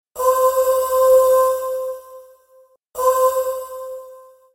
女性のやさしいハミングが、通知音として心安らぐ瞬間を提供します。